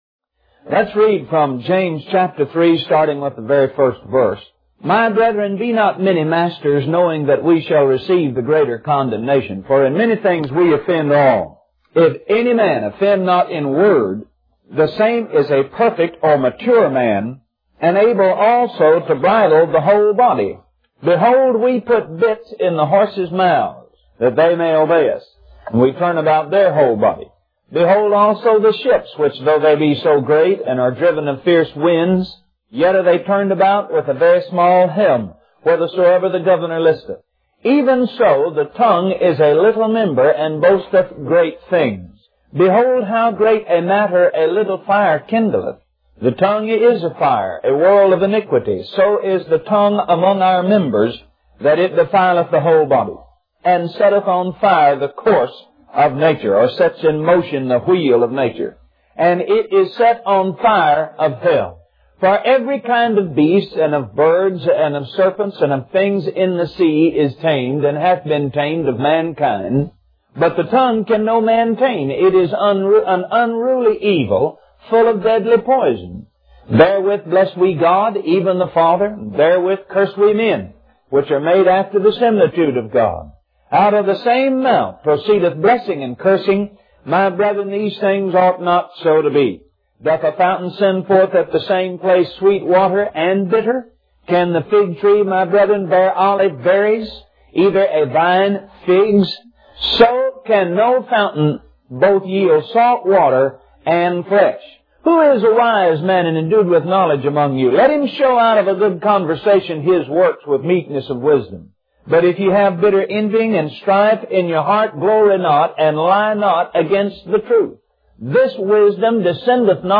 Index of /Audio/Sermons/Guest_Speakers/Kenneth_Copeland/